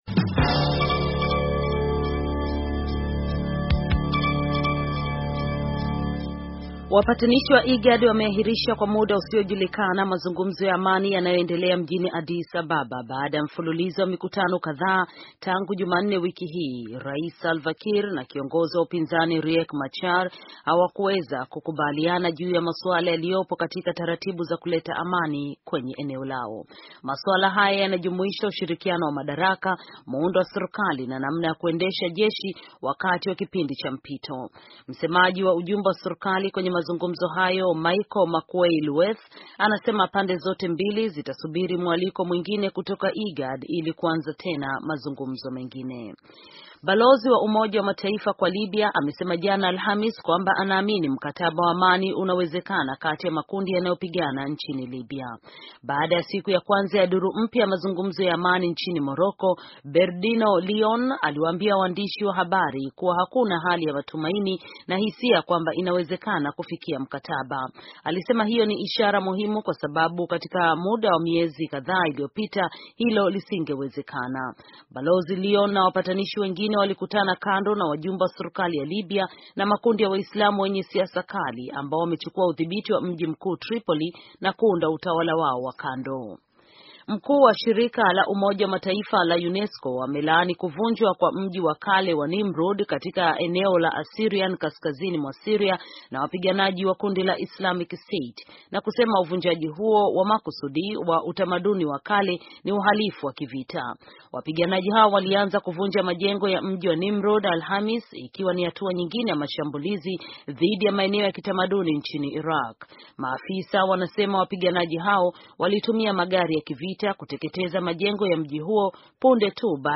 Taarifa ya habari - 4:48